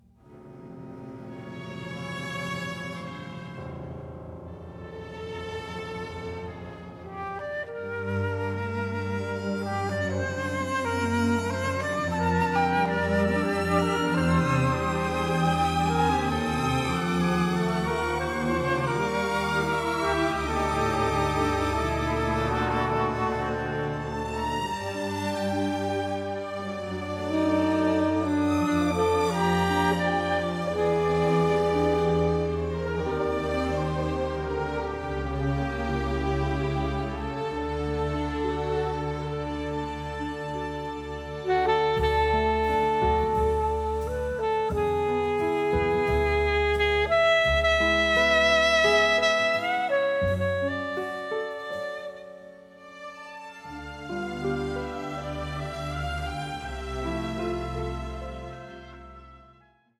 noir score
record the music in Paris